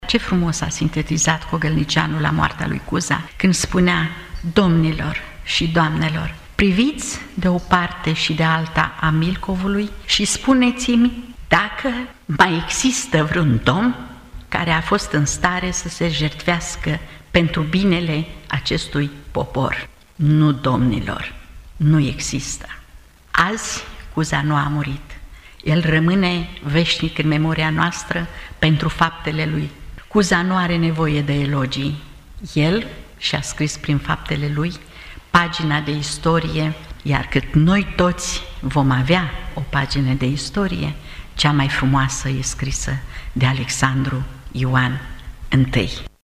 În contextul marcării a 163 de ani de la Unirea Principatelor Române, la Muzeul Municipal “Regina Maria” din Iaşi a fost vernisată expoziţia cu tema “Alexandru Ioan Cuza – Domn al epocii sale”.